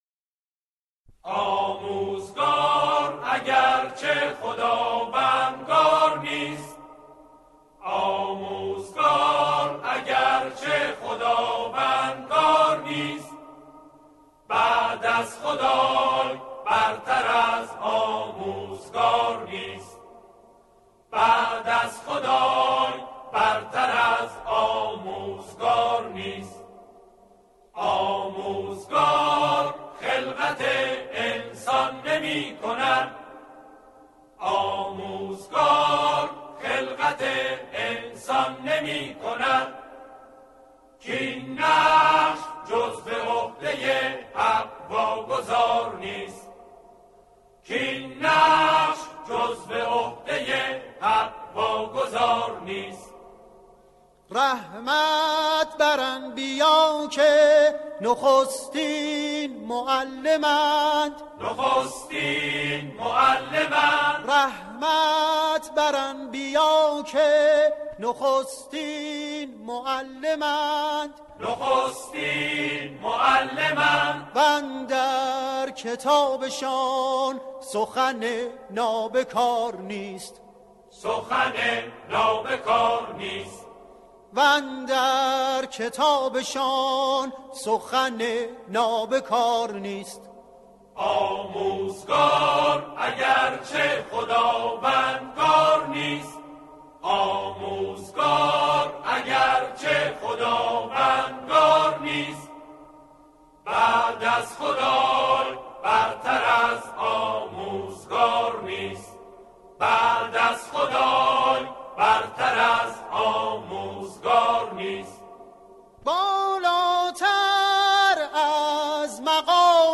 تنظیم آن به صورت آکاپلا است
همخوانی گروه کر